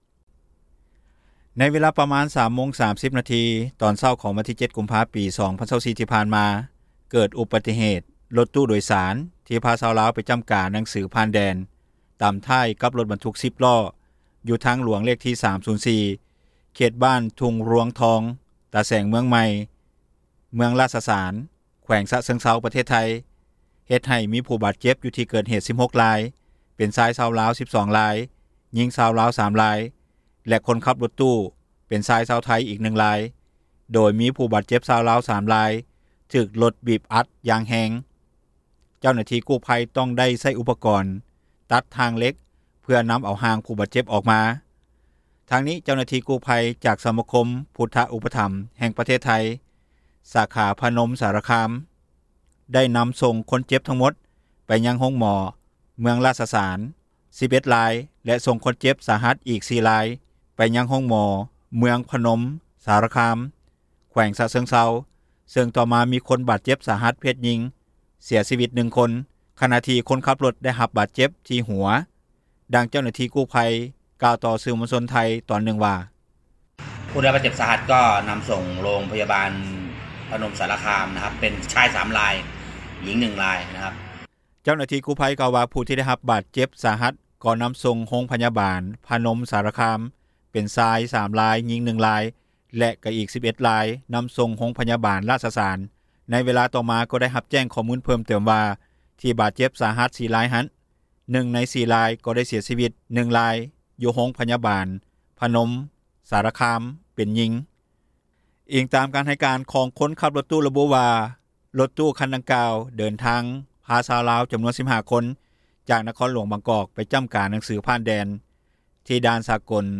ດັ່ງທີ່ ເຈົ້າໜ້າທີ່ກູ້ໄພ ກ່າວຕໍ່ສື່ມວນຊົນຂອງໄທຍ ຕອນນຶ່ງວ່າ: